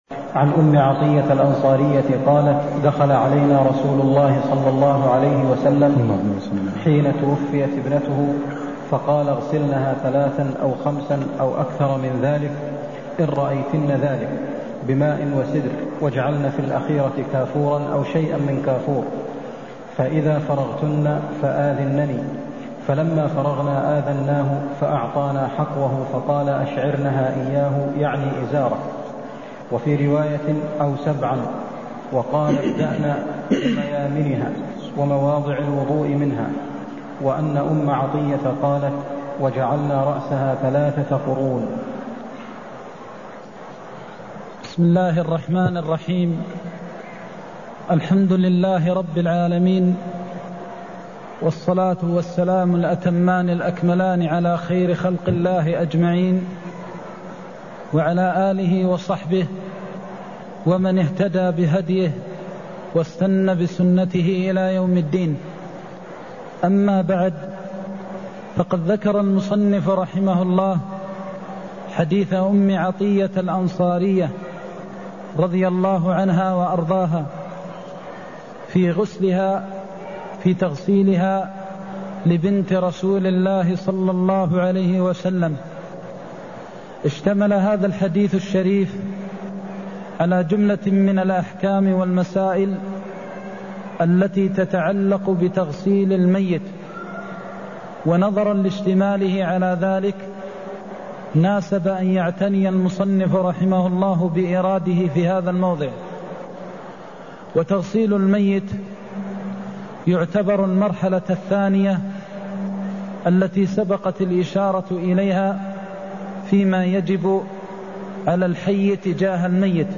المكان: المسجد النبوي الشيخ: فضيلة الشيخ د. محمد بن محمد المختار فضيلة الشيخ د. محمد بن محمد المختار صفة غسل الميت (153)